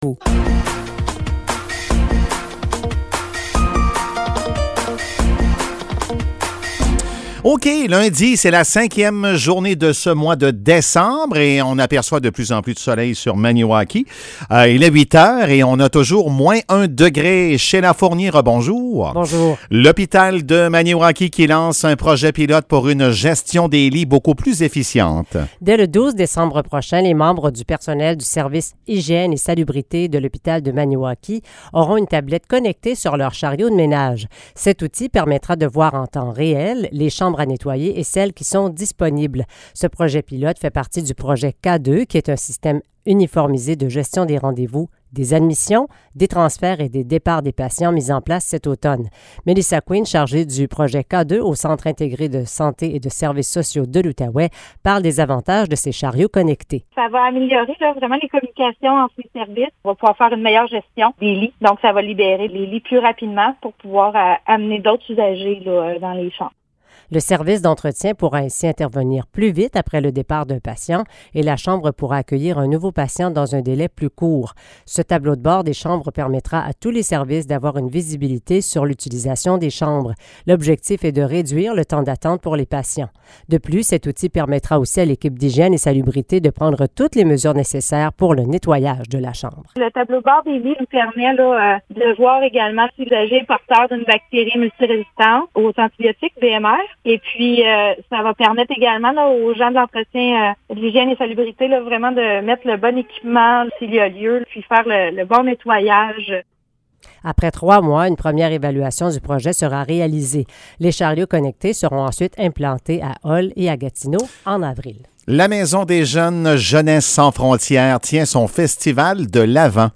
Nouvelles locales - 5 décembre 2022 - 8 h